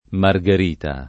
marger&ta] (antiq. margarita [margar&ta]) s. f. («perla; fiore») — con M- maiusc. il nome della Margherita, partito politico (2002-7) — sim. il pers. f. Margherita, i cogn. Margarita, -ti, -to, Margherita, -ti, -to, e il top. Margarita (Piem.), antiq. la Margarita, da cui il cogn. Solaro della Margarita